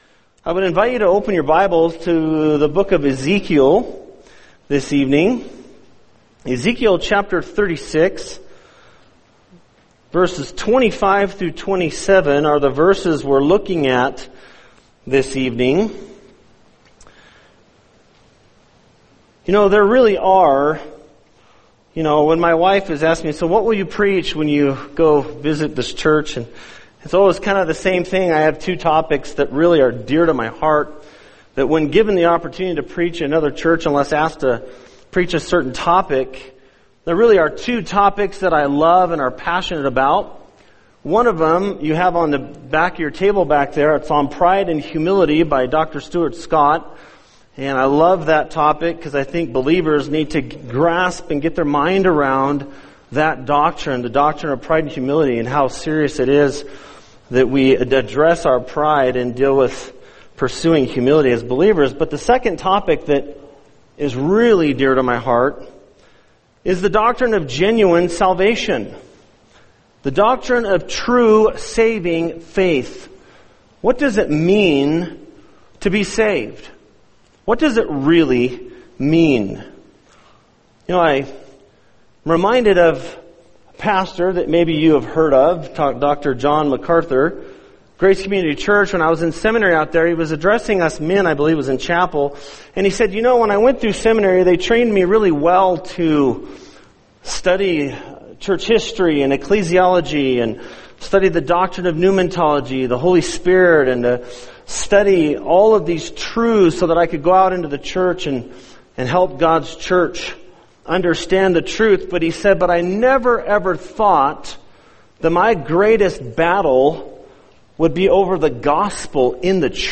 [sermon] Ezekiel 36:25-27 The Nature Of Genuine Salvation | Cornerstone Church - Jackson Hole